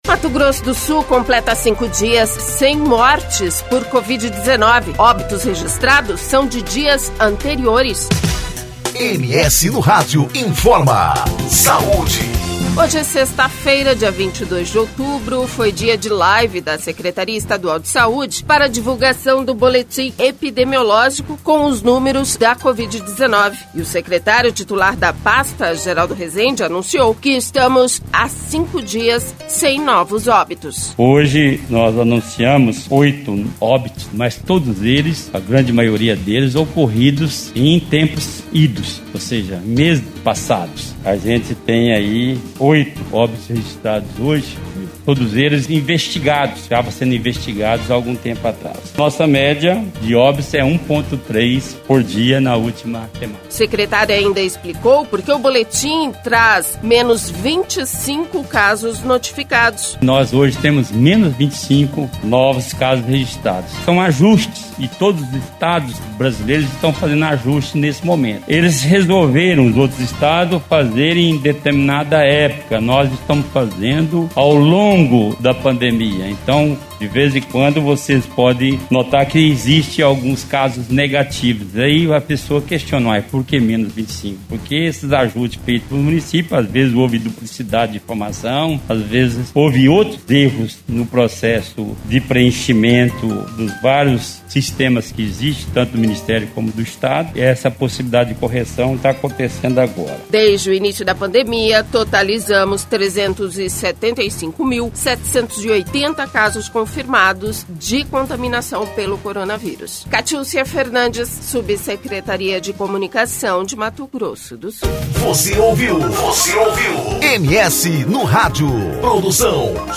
Hoje, sexta-feira, dia 22 de outubro, foi dia de live da Secretaria Estadual de Saúde para divulgação do boletim epidemiológico. E o secretário titular da pasta, Geraldo Resende anunciou que estamos a cinco dias sem novos óbitos.